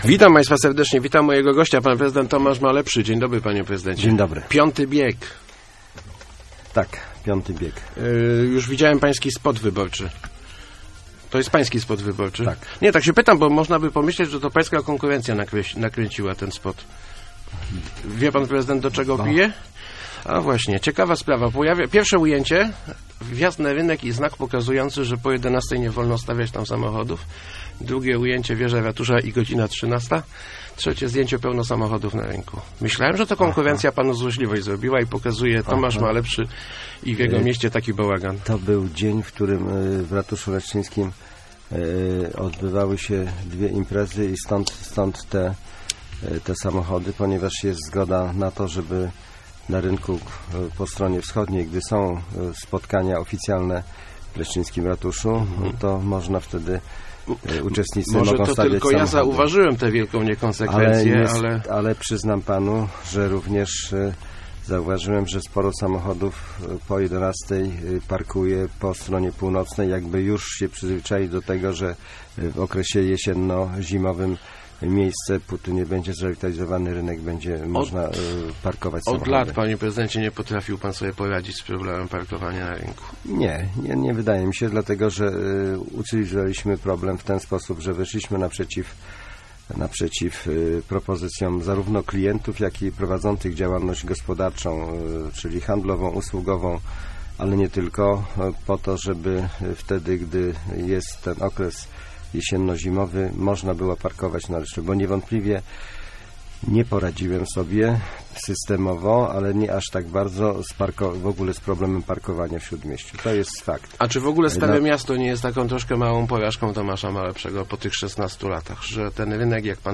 Wysokie nagrody dla prezesów miejskich spółek są zasłużone - mówił w Rozmowach Elki prezydent Leszna Tomasz Malepszy.